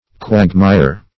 Quagmire \Quag"mire`\, n. [Quake + mire.]